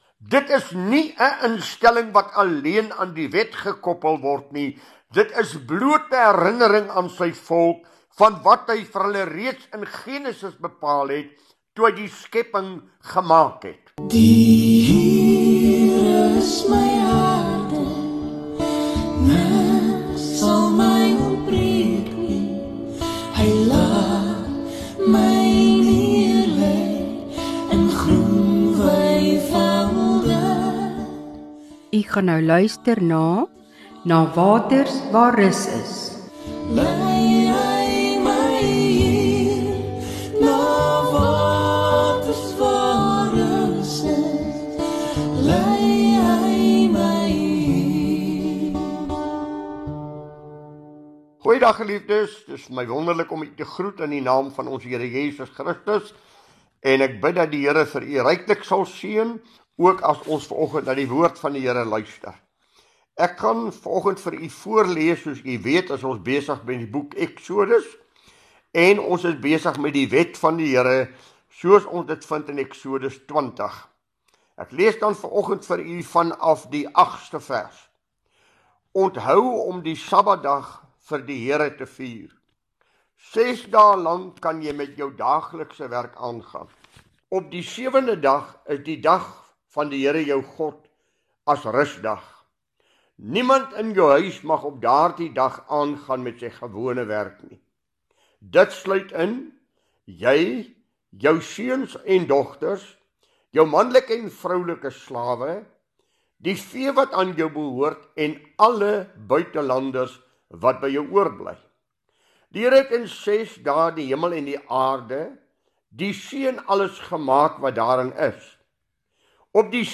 DIE PREDIKER BEHANDEL DIE WET VAN DIE HERE EN STAAN STIL BY DIE 4DE GEBOD OOR DIE SABBAT EN DIE KORROLASIE TUSSEN DIE OU EN NUWE VERBOND.